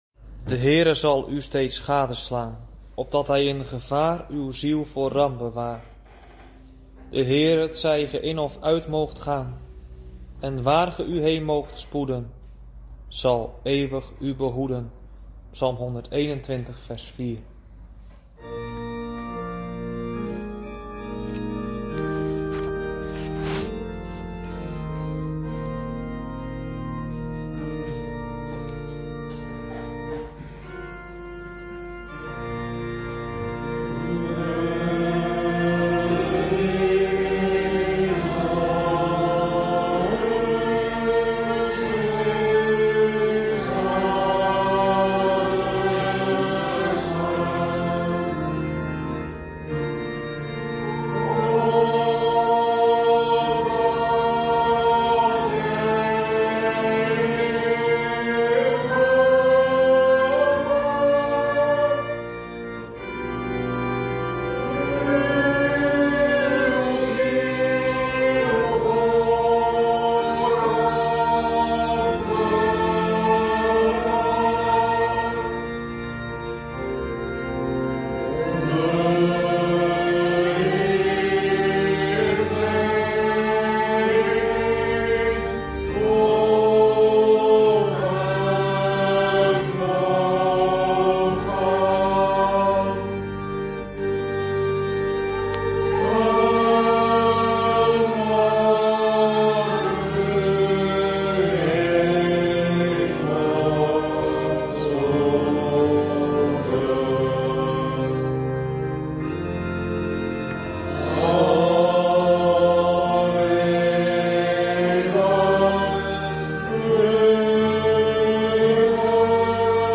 LEZING 32 - DORDTSE LEERREGELS - H5, ART. 3- DE VOLHARDING VAN CHRISTUS IN HET LEVEN VAN PETRUS